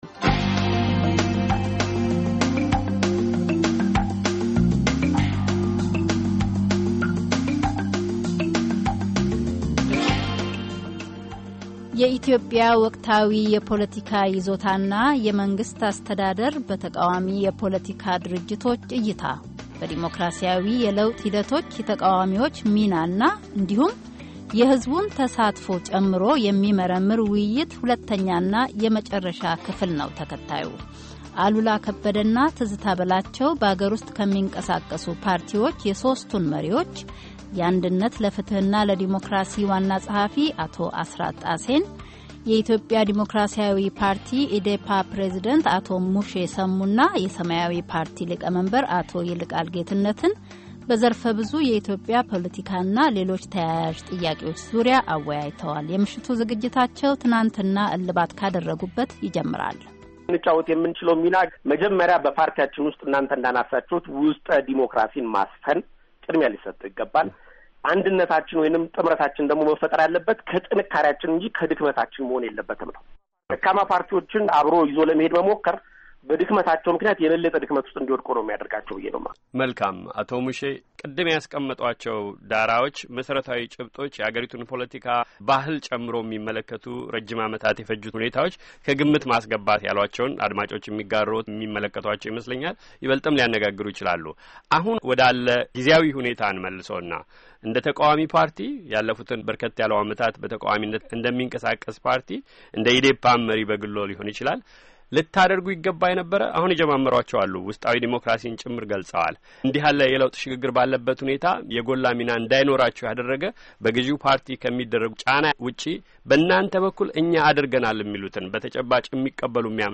ውይይት በኢትዮጵያ የተቃዋሚ ፓርቲዎች ሚናና ወቅታዊ የፖለቲካ ጉዳዮች ዙሪያ
የኢትዮጵያ ወቅታዊ የፖለቲካ ይዞታና የመንግስት አስተዳደር በተቃዋሚ የፖለቲካ ድርጅቶች እይታ፤ በዲሞክራሲያዊ የለውጥ ሂደቶች የተቃዋሚዎችን ሚናና እንዲሁም የሕዝቡን ተሳትፎ ጨምሮ የሚመረምር ውይይት ነው።